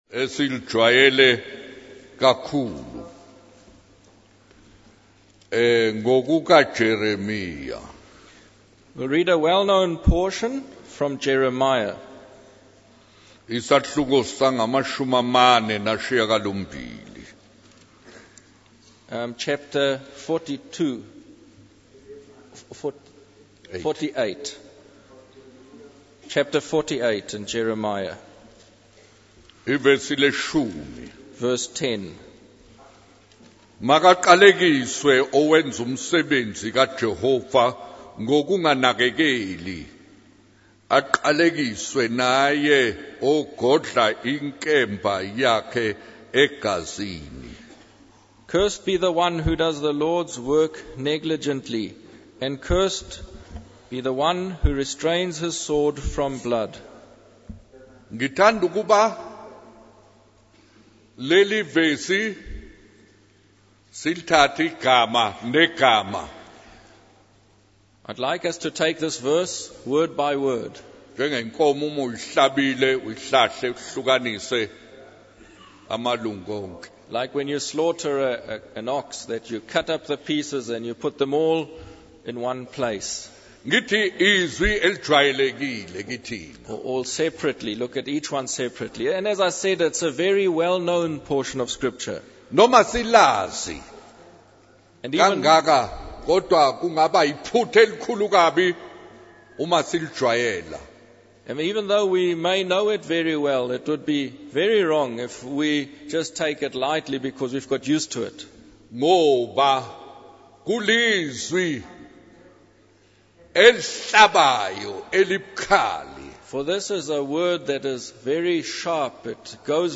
In this sermon, the preacher emphasizes the importance of not being lazy or slack in our work, especially when it comes to God's work. He references Matthew 28:19, where Jesus commands his disciples to go into the world and make disciples, teaching them to obey his teachings.